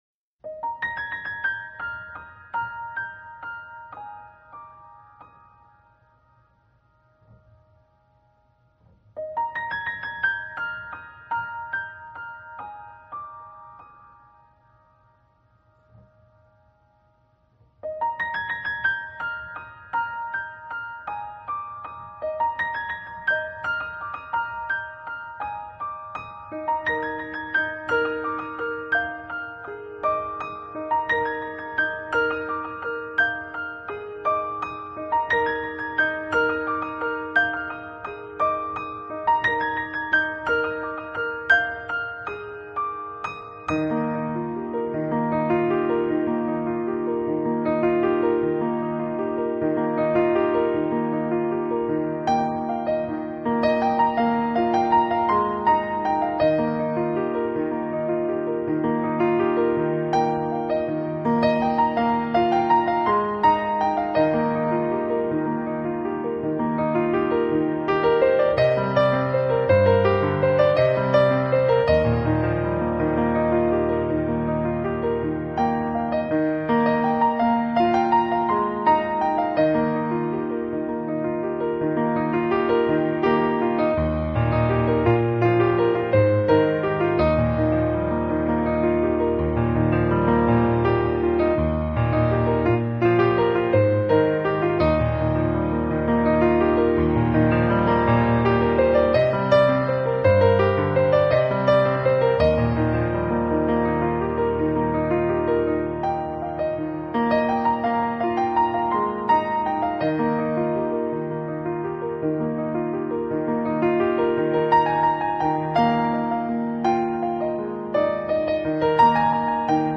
【柔美钢琴】
专辑类型：New Age
使其除了具有钢琴一贯的柔美外，还带上了些许的宗教色彩。